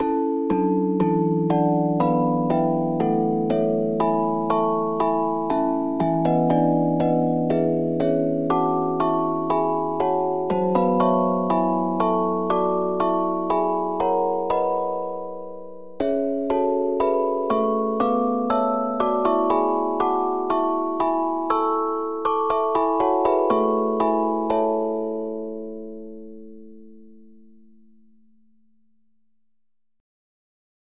ADMIRE's output is sent back across the MIDI interface to a synthesizer for playback.
While the harmonies do not match the traditional Western harmonies for the pieces, they are still consonant.